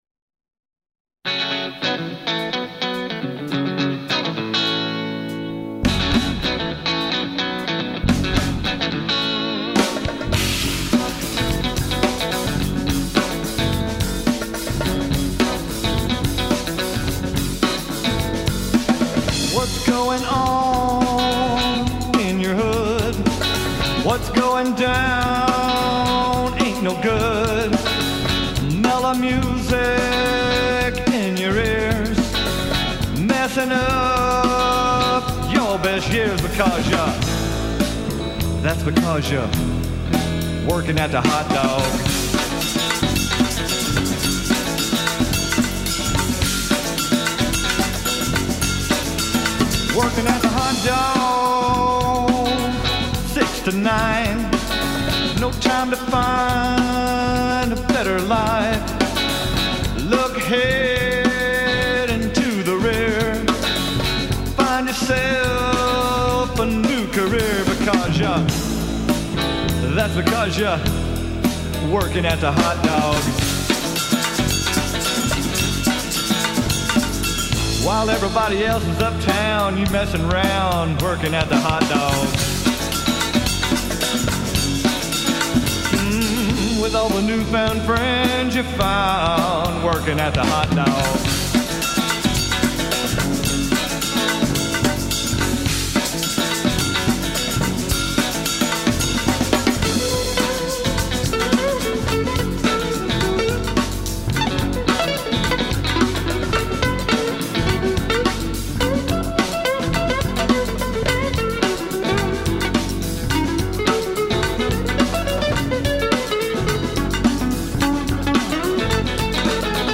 open up a four barrel of V-8 ROCK~A~BILLY RHYTHM & BLUES.